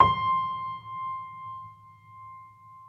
Vintage_Upright